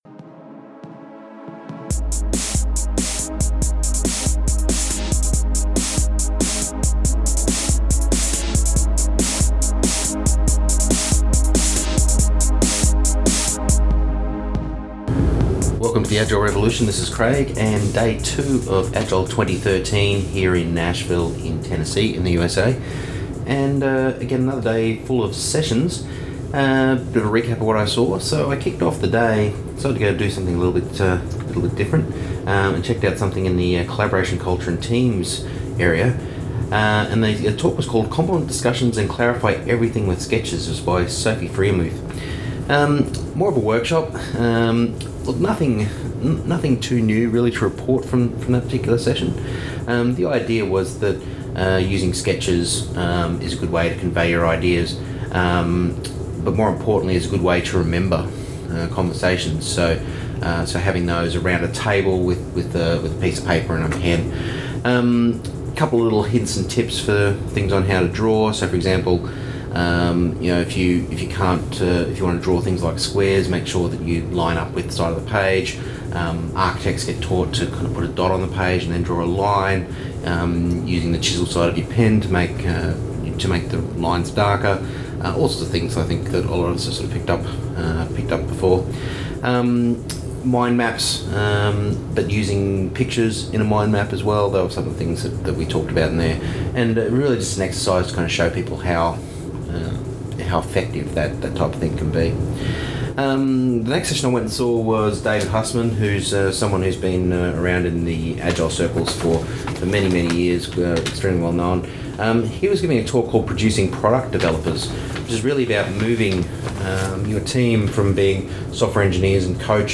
continues his daily reports from Agile 2013 in Nashville with some observations on the sessions he attended on day 2.